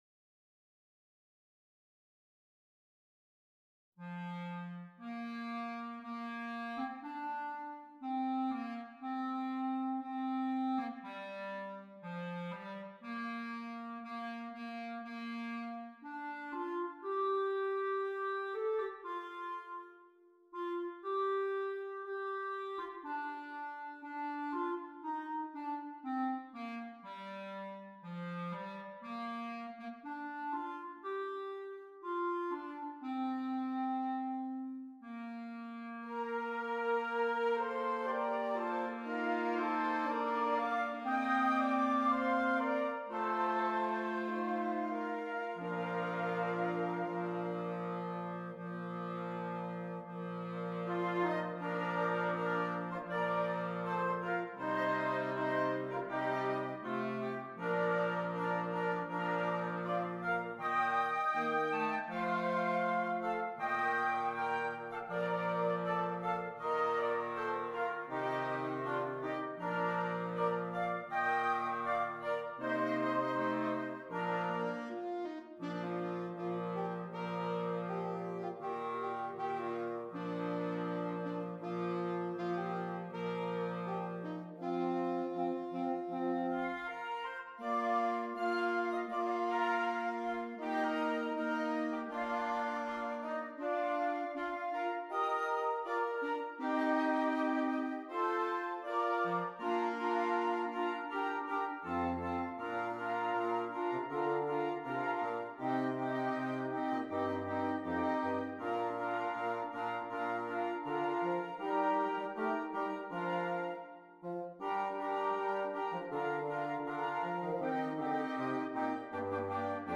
Interchangeable Woodwind Ensemble
Traditional